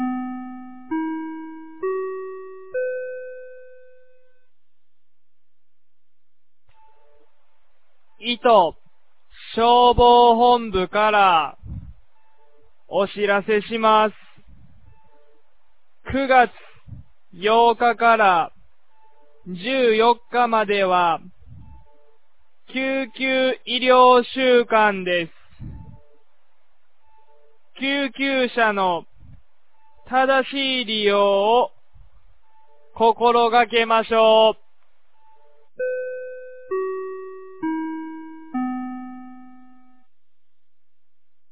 2024年09月02日 10時01分に、九度山町より全地区へ放送がありました。
放送音声